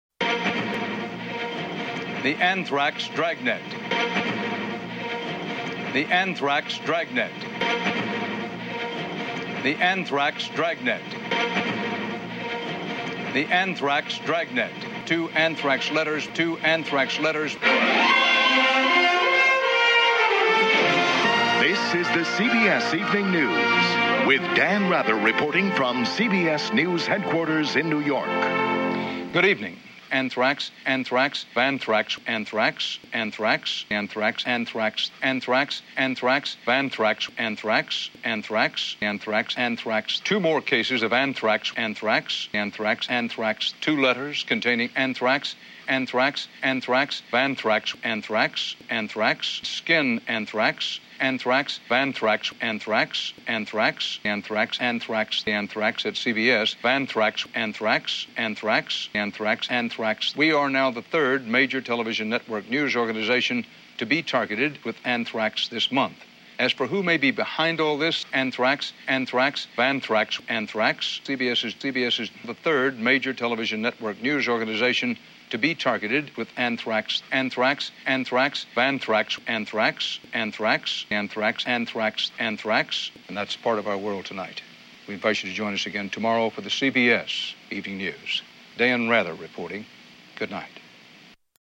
Dan Rather Collage
You may also hear the occasional cuss word in some translations: you have been warned.